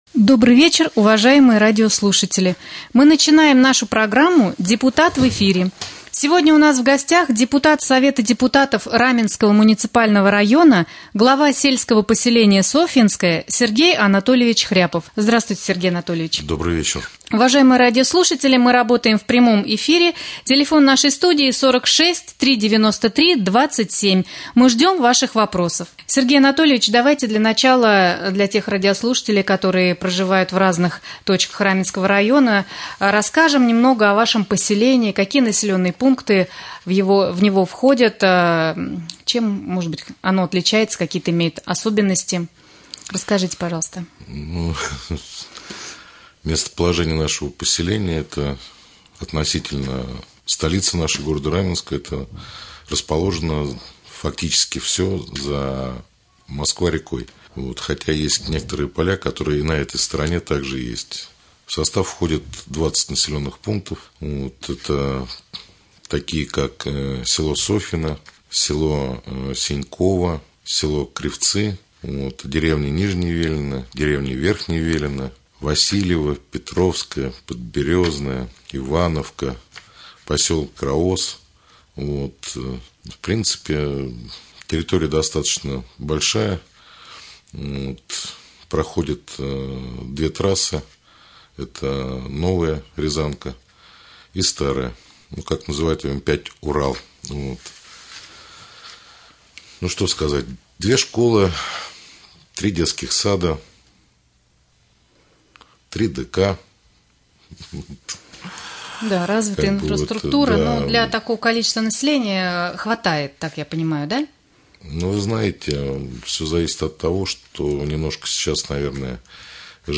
Прямой эфир. Гость студии глава сельского поселения Софьинское С.А.Хряпов.